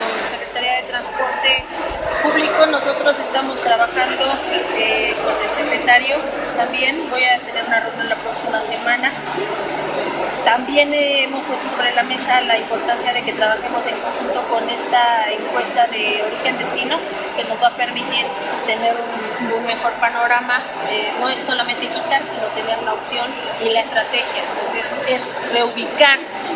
En entrevista posterior a tomar protesta a 375 integrantes de mesas directivas de vecinos 2019 -2021 del municipio de Puebla, Rivera Vivanco abundó que se efectúan mesas de trabajo a fin de analizar la situación de el transporte público, con la finalidad de liberar la carga que en la actualidad se presenta en el primer cuadro de la ciudad.